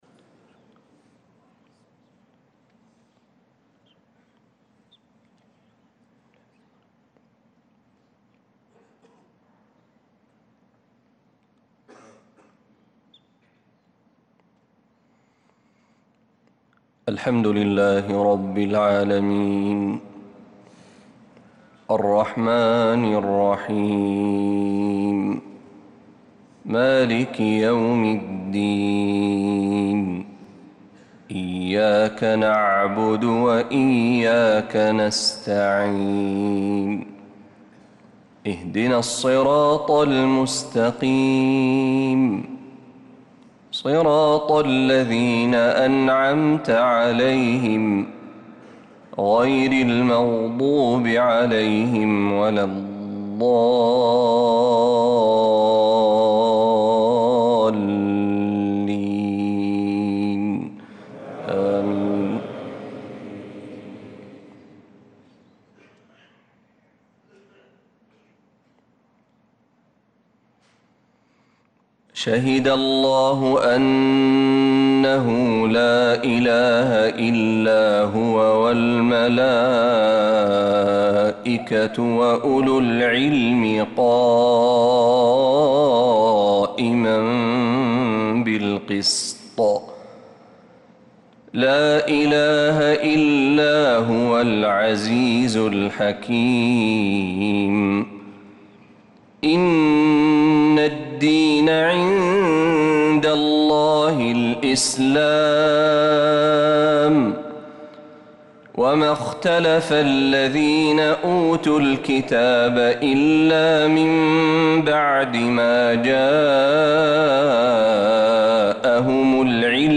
صلاة الفجر للقارئ محمد برهجي 25 ربيع الآخر 1446 هـ
تِلَاوَات الْحَرَمَيْن .